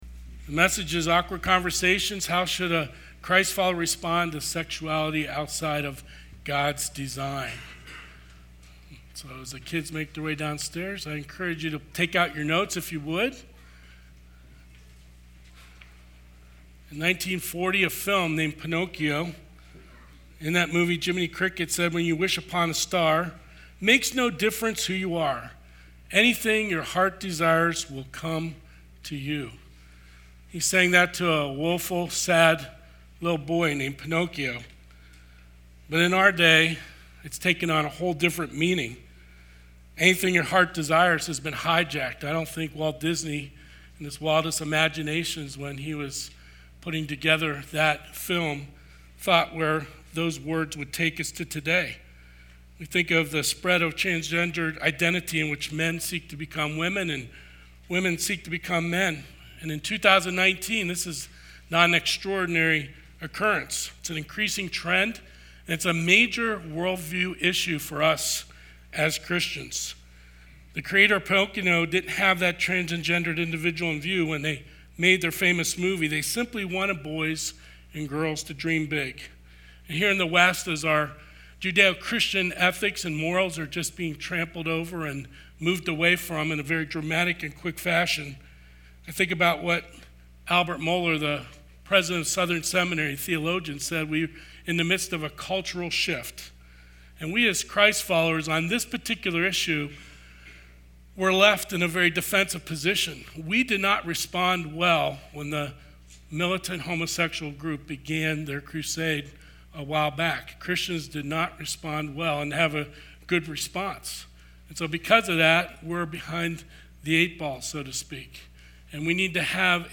Sermons | Pleasant View Baptist Church